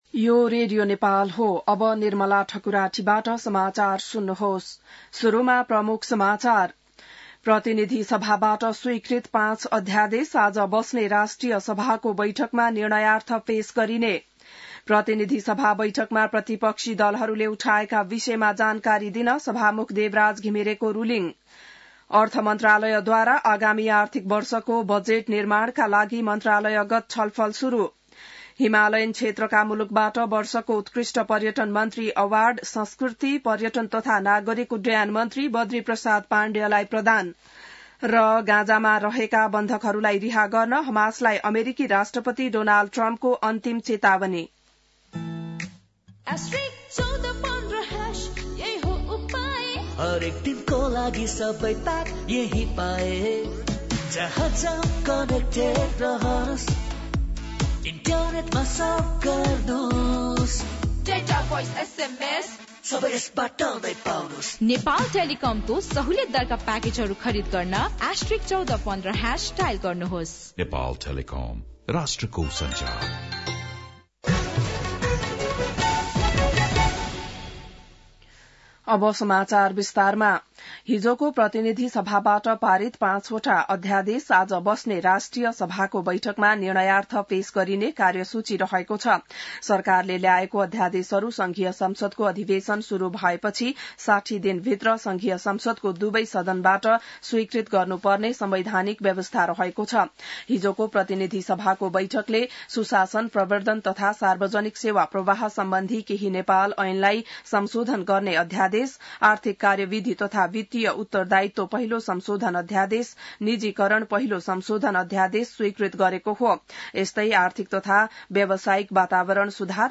बिहान ७ बजेको नेपाली समाचार : २३ फागुन , २०८१